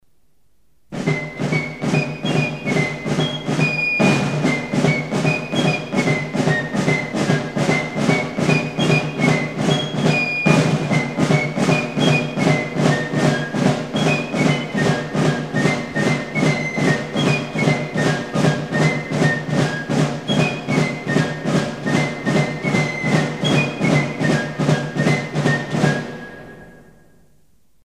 Méthode de tambour d’ordonnance
signal du réveil en campagne.
Batterie du camp et des places de guerre, elle a pour objet de tenir les troupes en éveil.
Sa cadence est de 110 pas à la minute